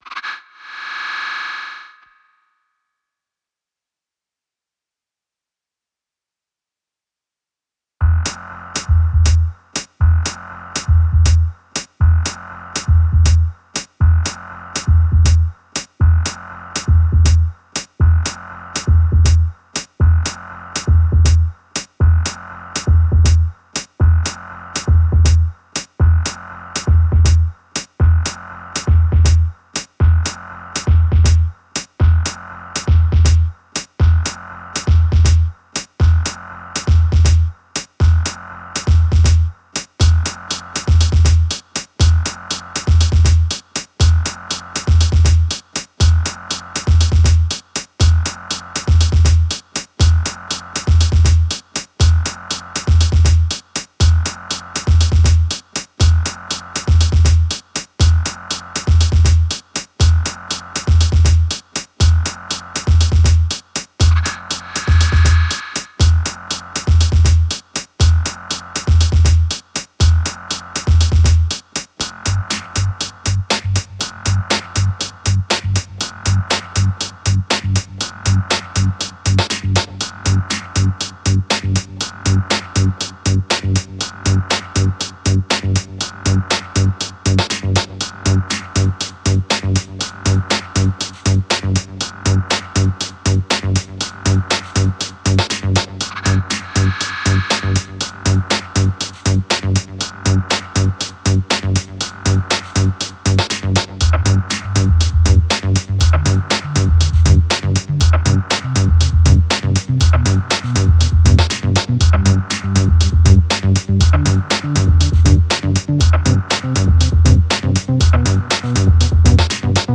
All Saints Church in Honiara auf den Salomonen